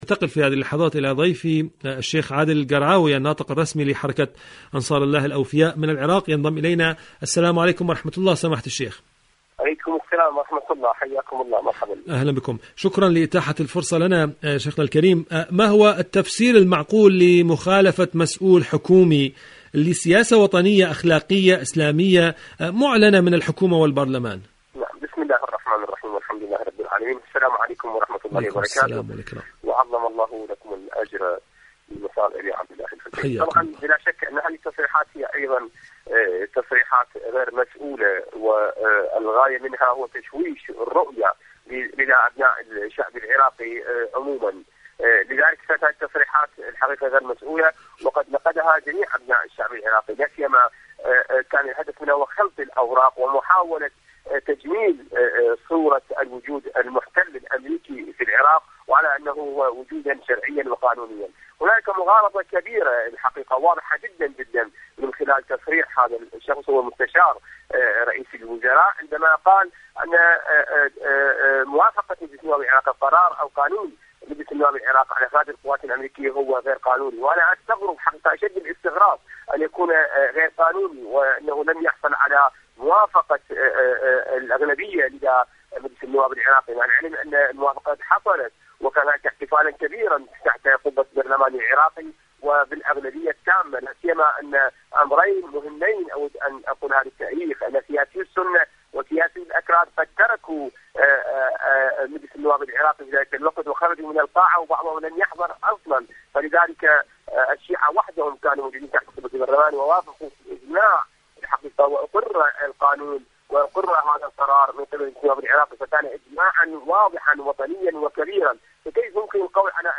إذاعة طهران-عراق الرافدين: مقابلة إذاعية